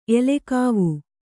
♪ elekāvu